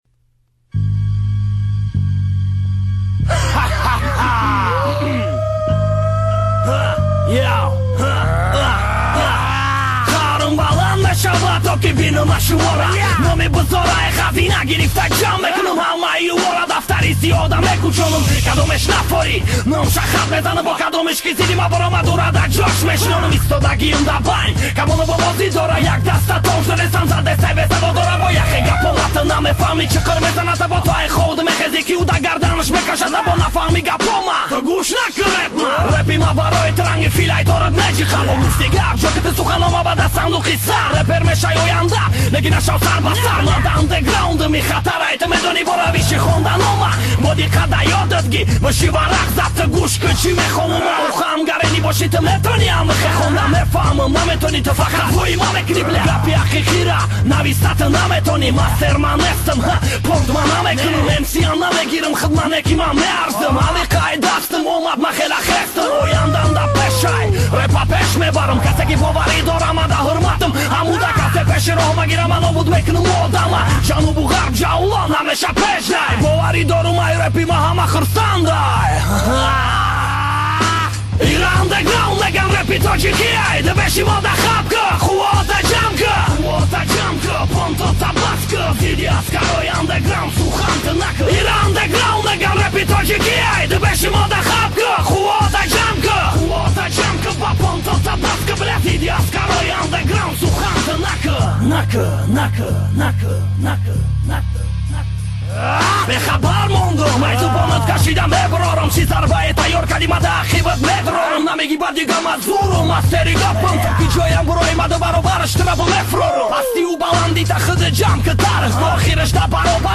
Главная » Каталог mp3 » Рэп / HIP HOP » UNDERGROUND PRO